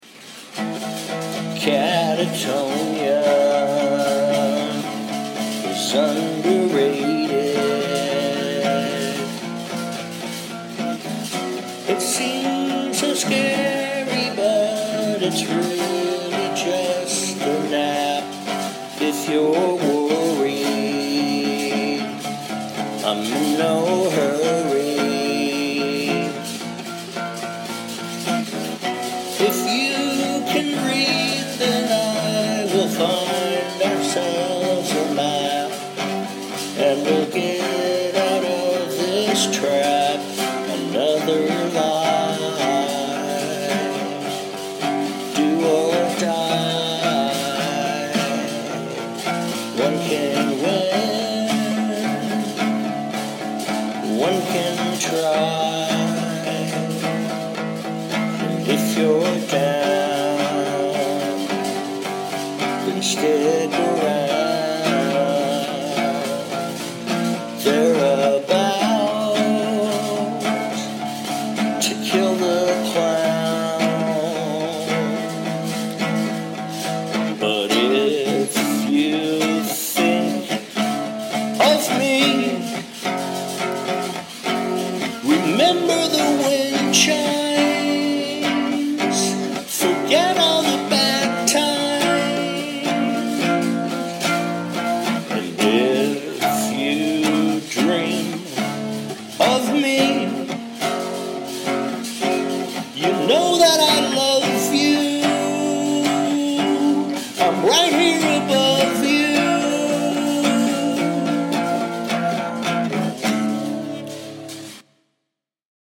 It's got dodgy singing and some of the voice wavers, but I swear there's something to this ending right?